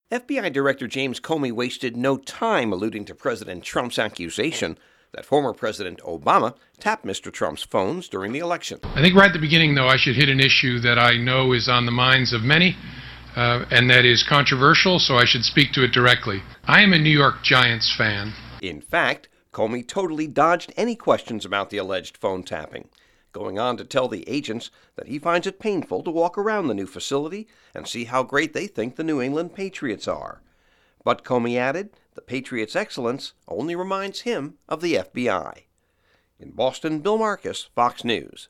FBI Director James Comey speaking in Boston Tuesday.